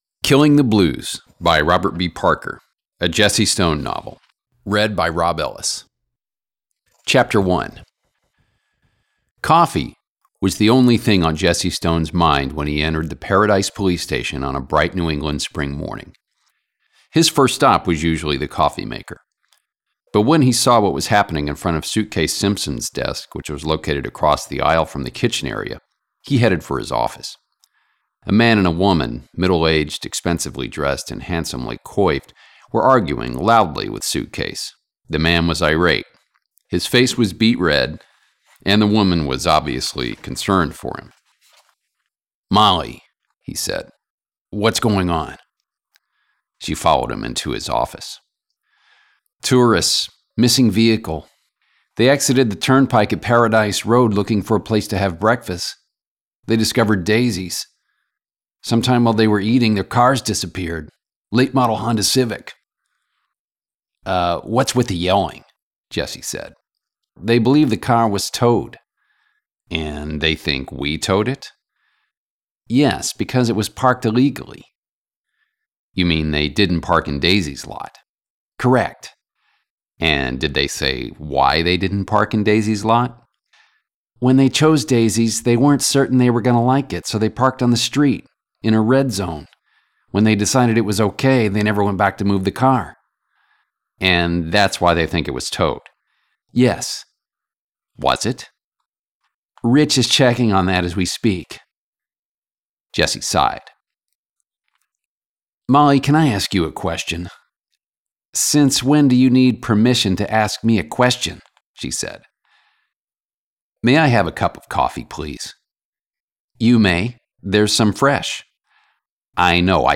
Trying to shore up my audiobook chops
Editing BTW is just basic and not perfect by any means.
1) your room has a bunch of echo
2) you're gating or noise reducing the heck out of it, so it goes silent in between sentences.
3) I can hear background noise, including page turns.
I'm in a temporary corporate apt since we just moved to Florida, and doing all my recording on a laptop in an untreated room. so this is definitely not my usual setup.